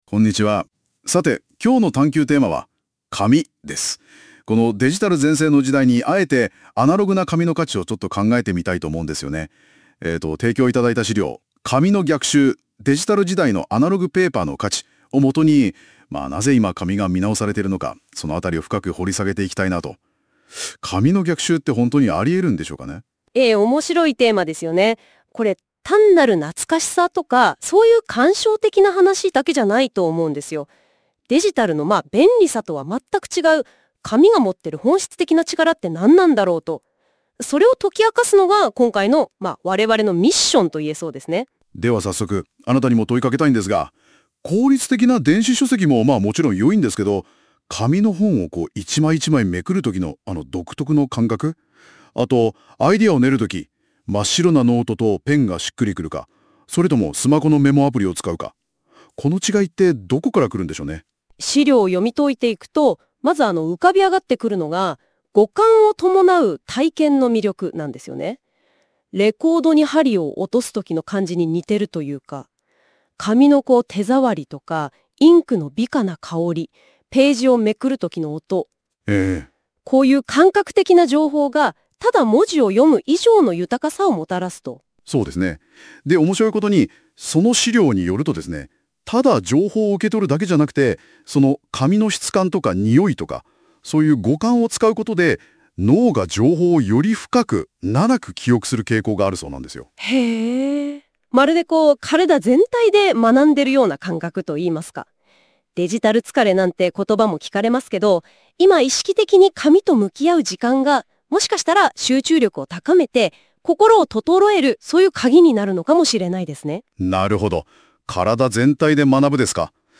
※ 少々アクセントや漢字の読みがおかしな箇所はありますが、とても分かりやすく話してくれています。